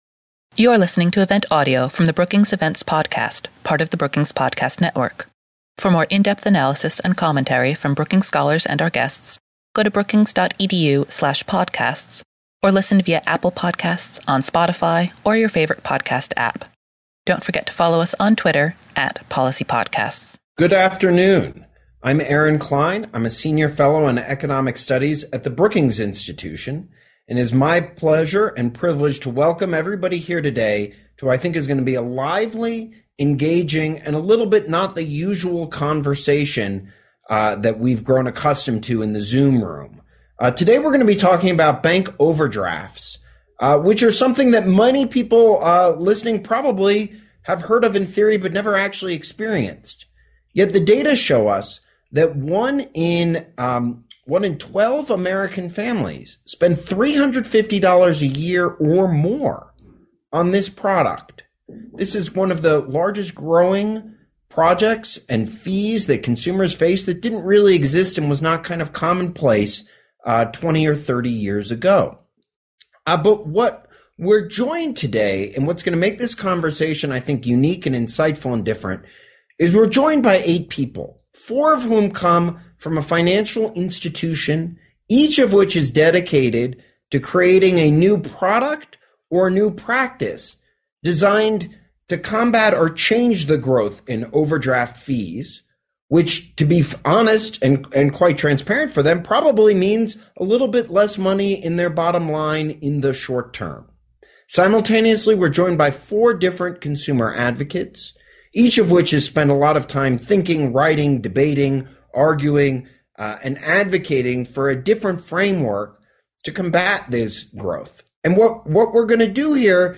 Thursday, July 15, the Center on Regulation and Markets hosts a conversation with banks, fintech firms, and consumer advocates on alternatives to overdraft.